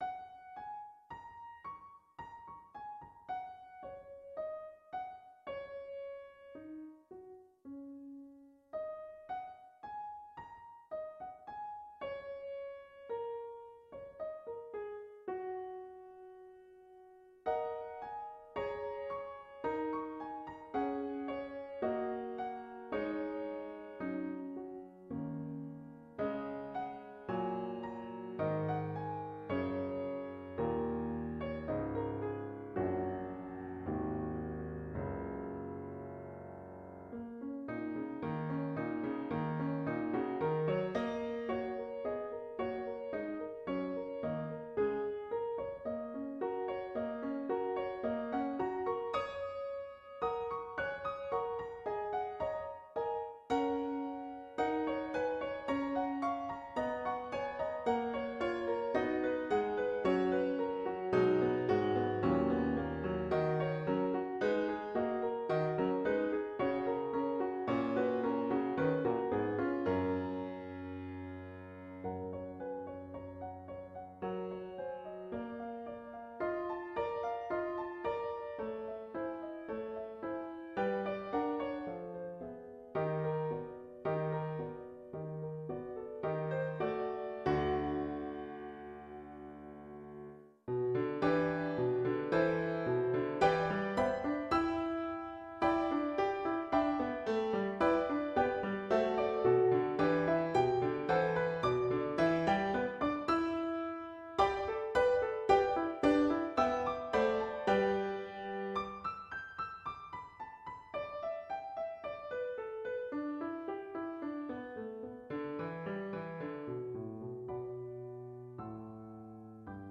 The melody is made up out of the pentatonic scale, 5 pitches, largely associated with Asian music. Think of the melody being played only on the black keys of the piano. It was written in the key of Gb major. The melody segues to G major, and then ends enharmonically in F# major, (Gb major only with sharps).